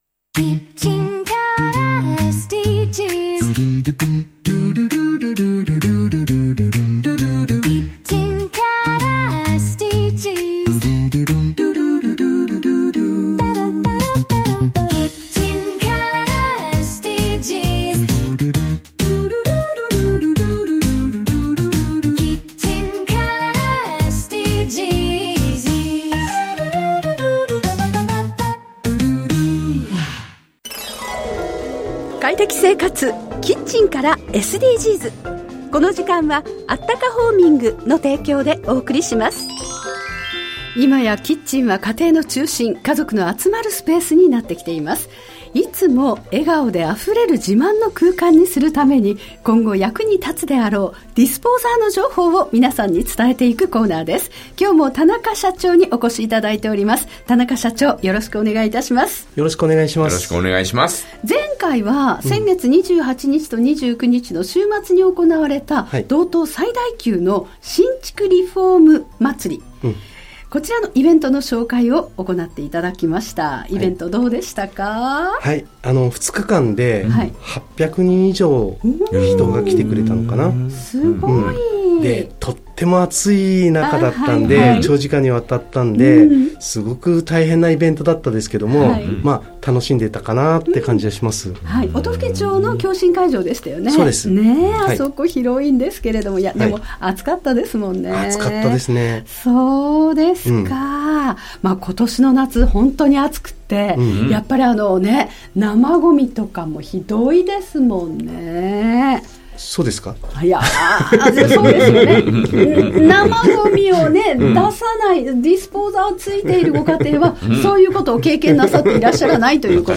【ラジオ】快適生活 キッチンからSDGs 放送中 （ じゃらら（JAGA）10時30分～45分 ）